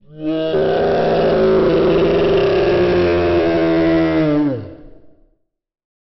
dragon_growl_1.wav